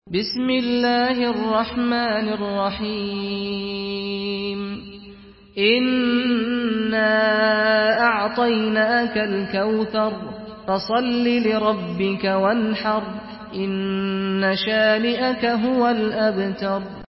سورة الكوثر MP3 بصوت سعد الغامدي برواية حفص
مرتل حفص عن عاصم